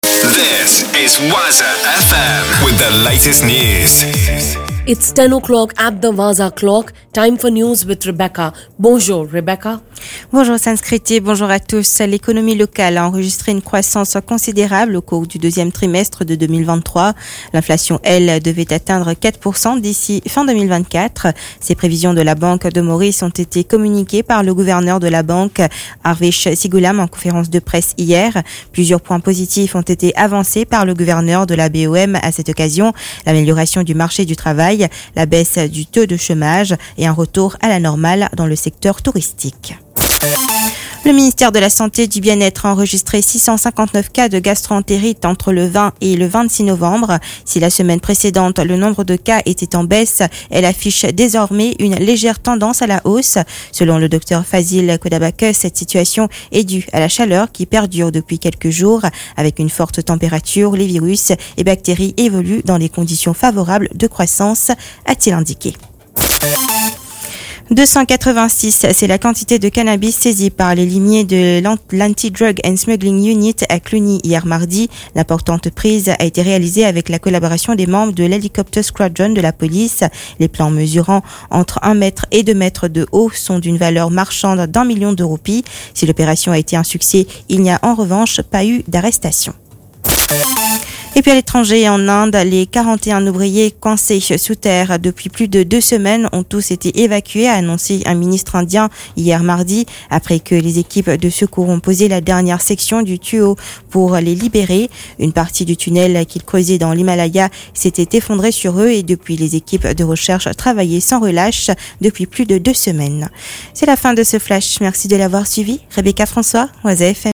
NEWS 10H - 29.11.23